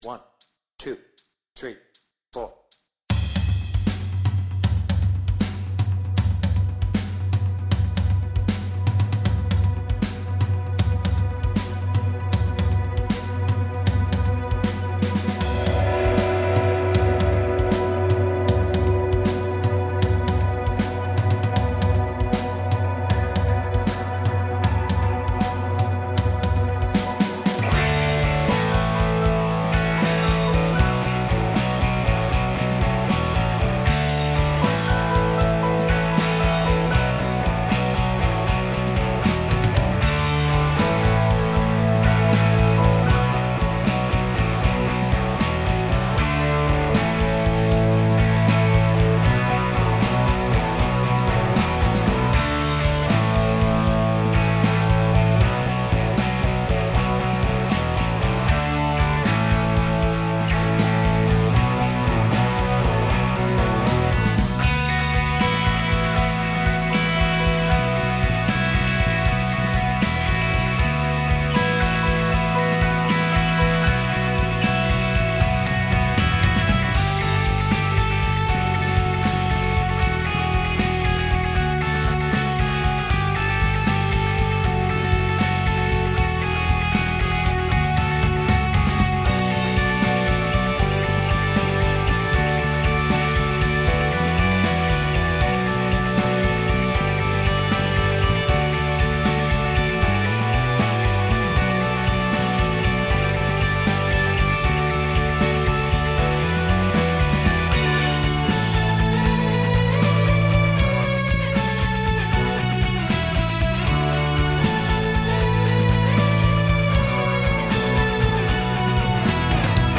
Without vocals
Based on 360° Tour and album